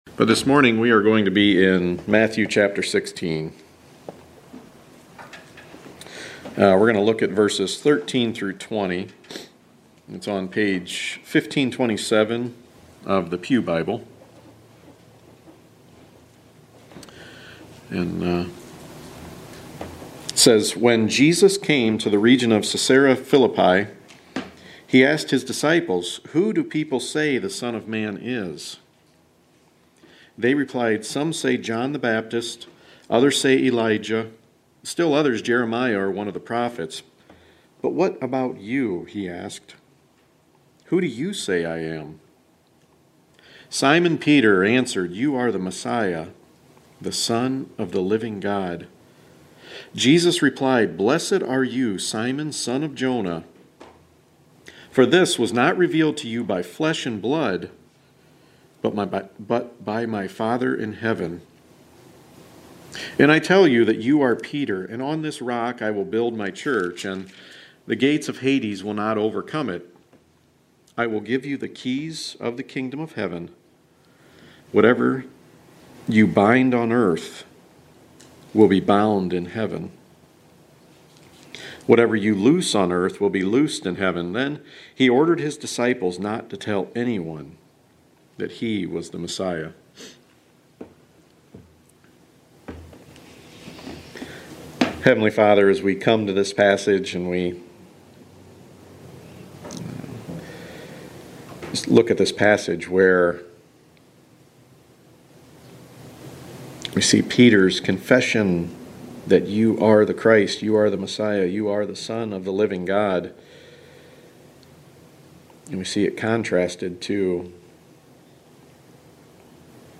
The Gospel of Matthew Series (25 sermons)